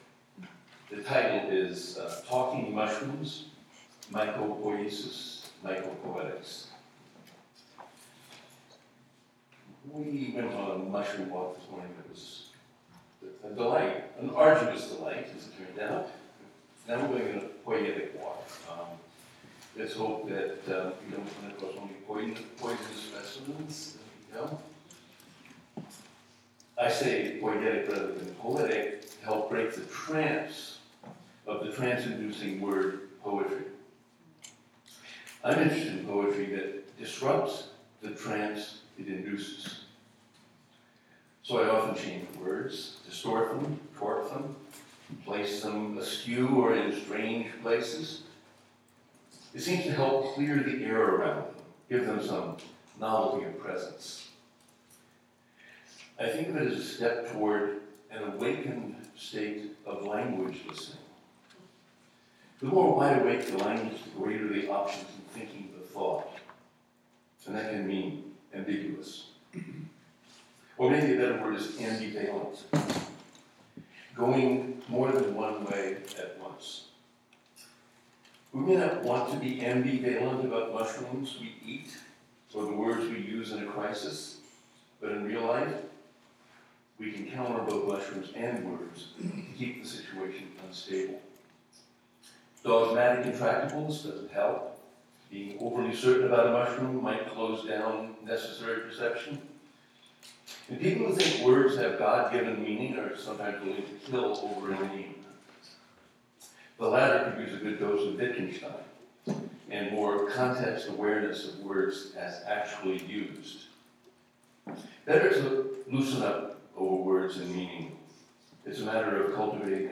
Organized by the John Cage Trust, John Cage Mycology Day, was a one-day event, featuring a mushroom walk and afternoon presentations, that celebrate Cage's passion for mushrooms and his multifarious contributions to mycology.